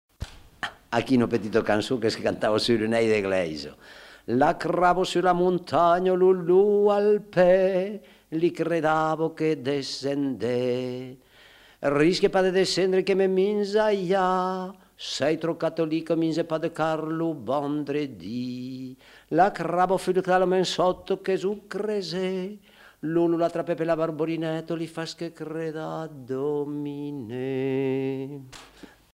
Aire culturelle : Haut-Agenais
Lieu : Lauzun
Genre : conte-légende-récit
Effectif : 1
Type de voix : voix d'homme
Production du son : récité